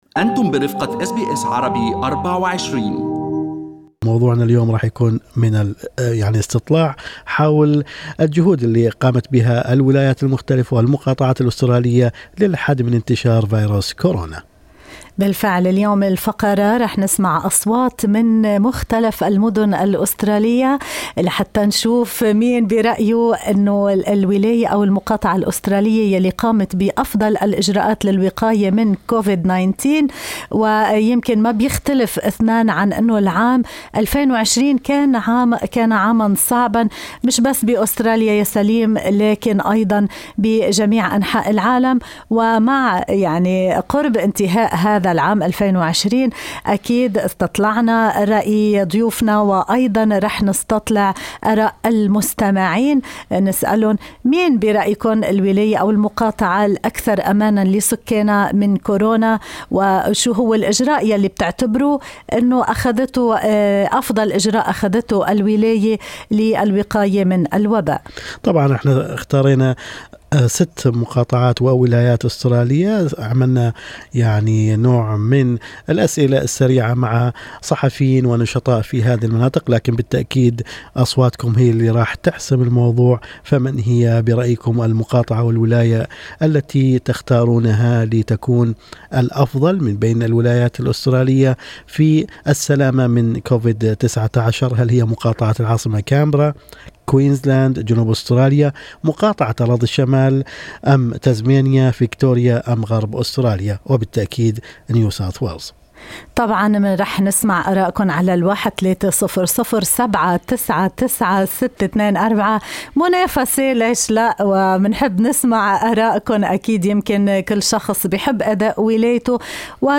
الاستطلاع شارك فيه صحفيون وناشطون من الجالية فضلاً عن مستمعي اس بي أس عربي 24